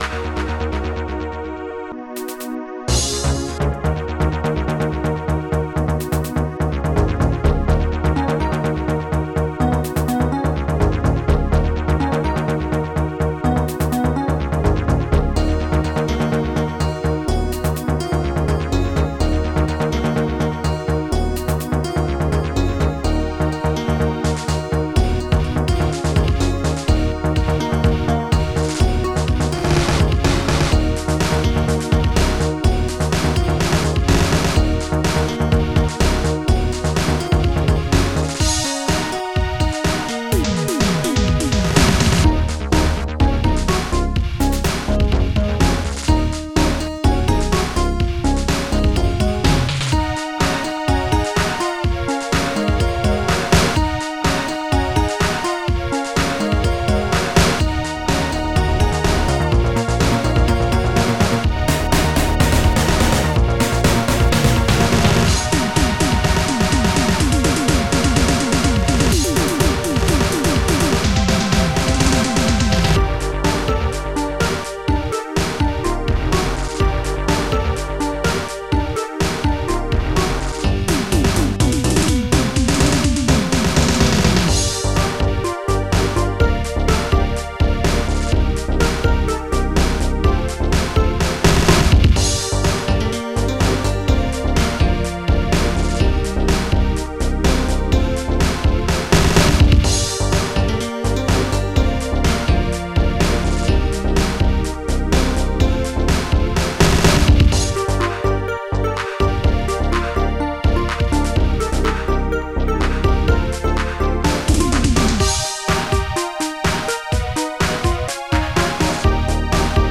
st-00:strings8-acc1
st-08:funkbdrum
st-09:tynepiano
st-04:crash3